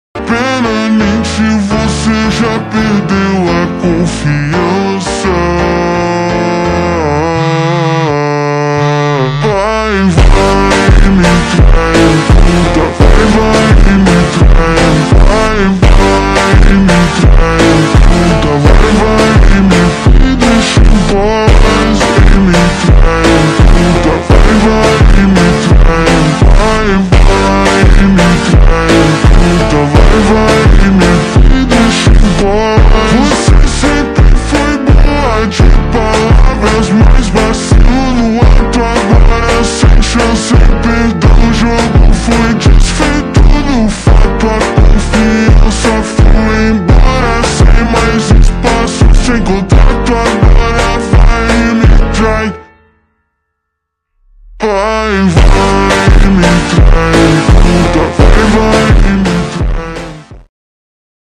Ultra Slowed